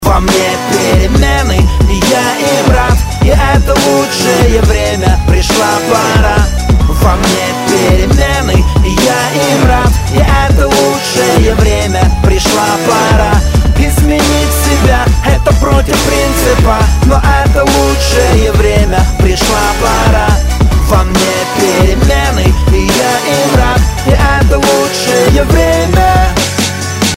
Категория: Рэп рингтоны